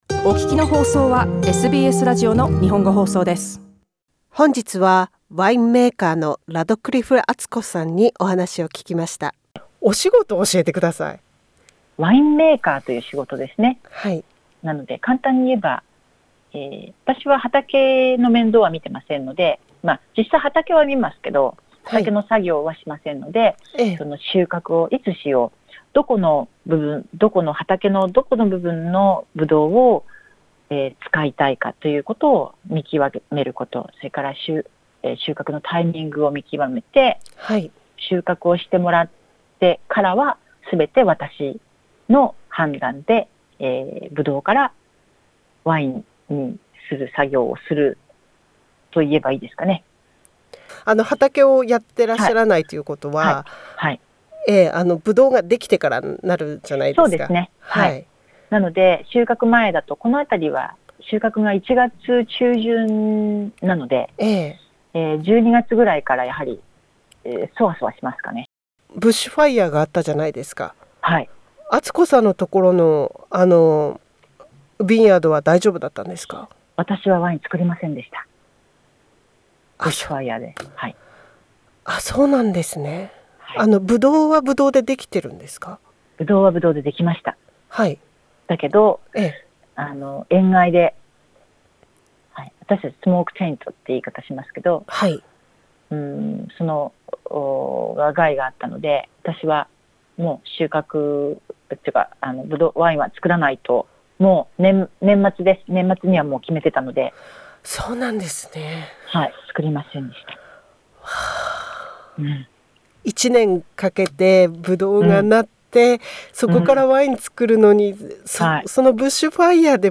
インタビューでは、今までのワイン・日本酒造りの経験について、また、現在ワイナリーで行っている、ワイン造り以外の活動についても聞いています。